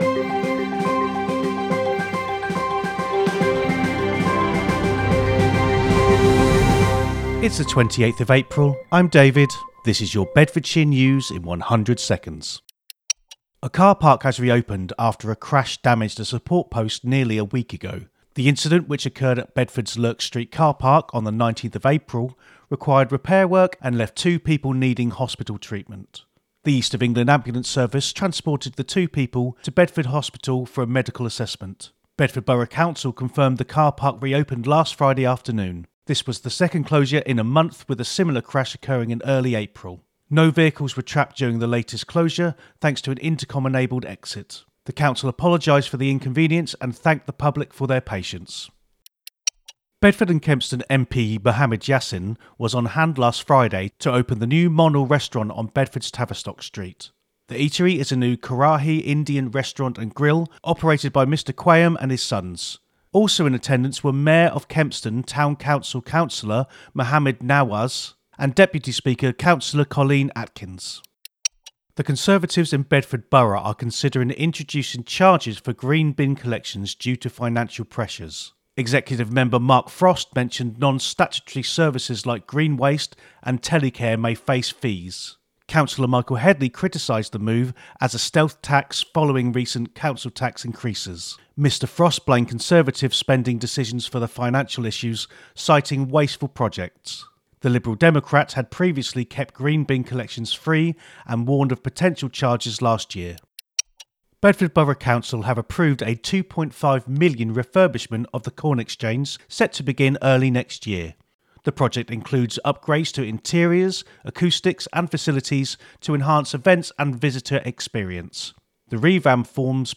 A free audio news roundup for Bedford and the greater Bedfordshire area, every weekday.